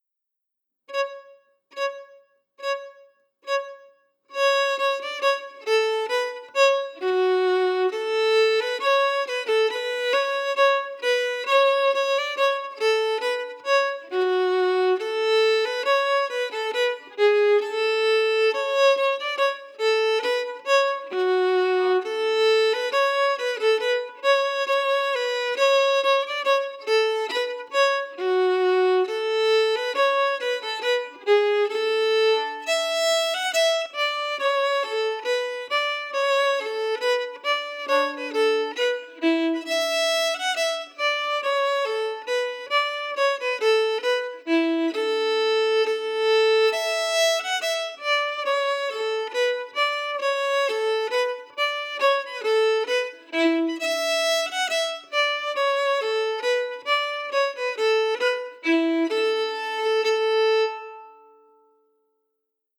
Key: A
Form: Reel